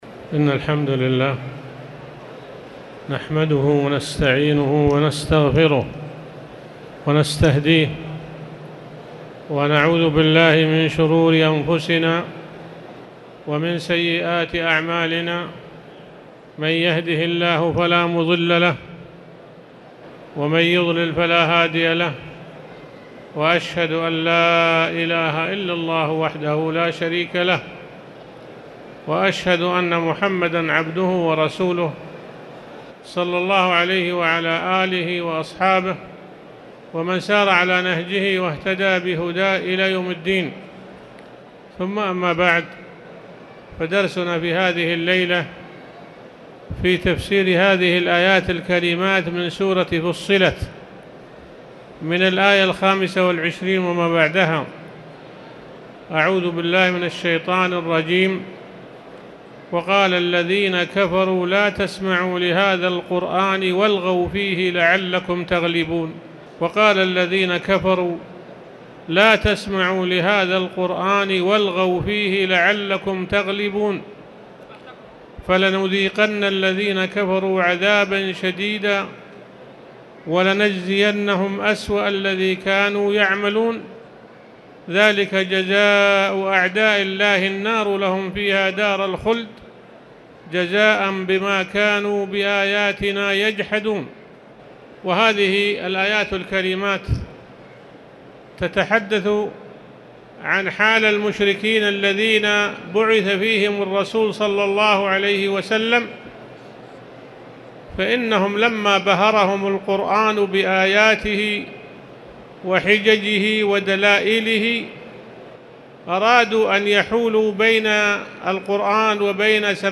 تاريخ النشر ٢٠ رجب ١٤٣٨ هـ المكان: المسجد الحرام الشيخ